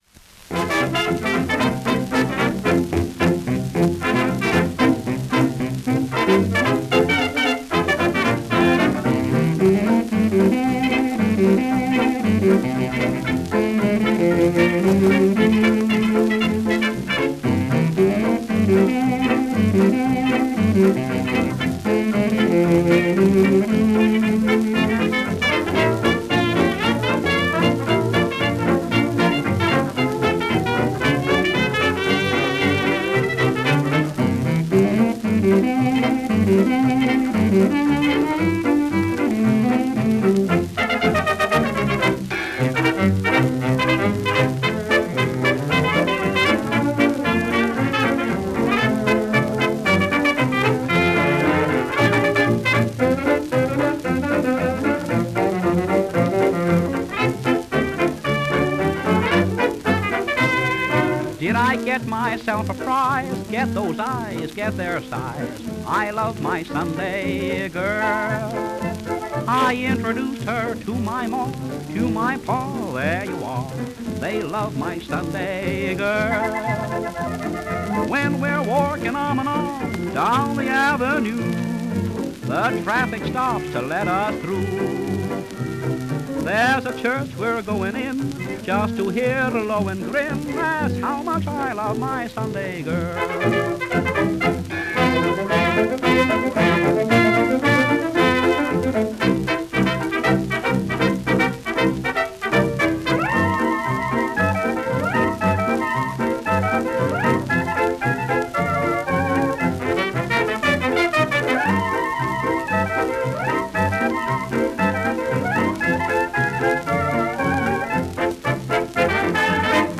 with vocal chorus And his orchestra
Fox-trot